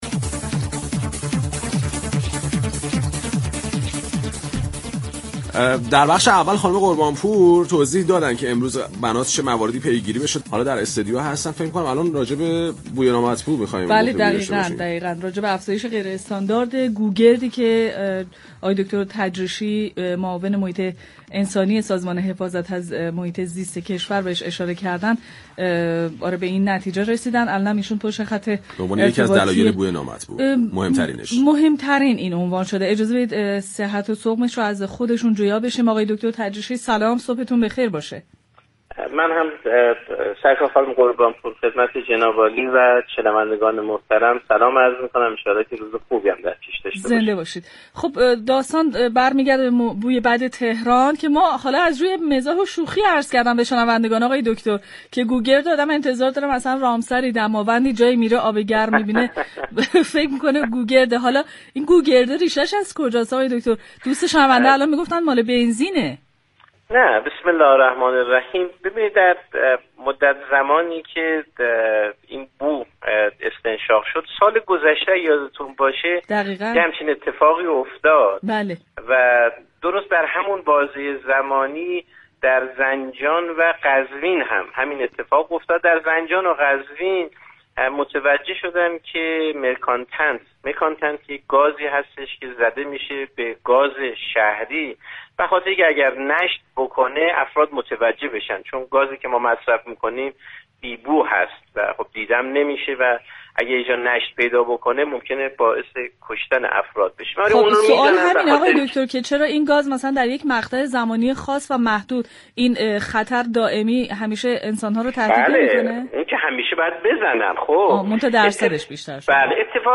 مسعود تجریشی معاون محیط انسانی سازمان محیط زیست در پارك شهر رادیو تهران درباره بوی نامطبوع شهر صحبت كرد.